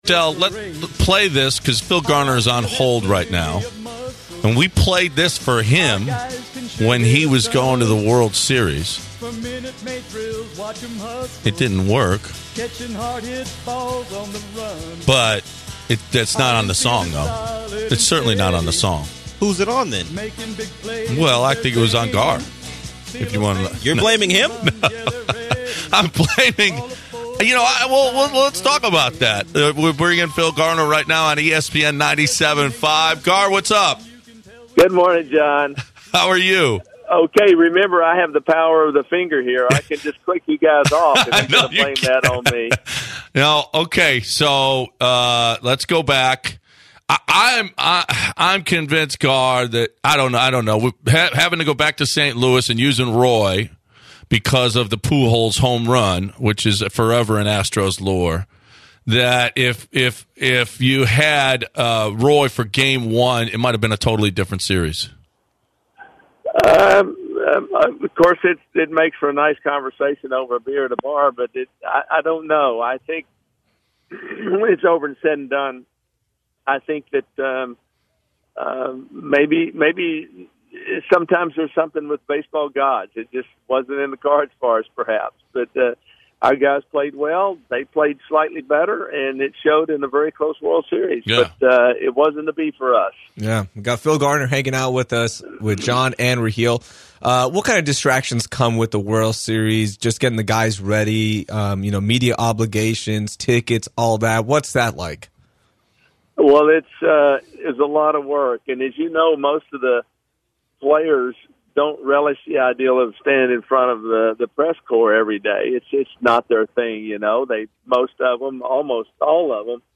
10/23/2017 Phil Garner Interview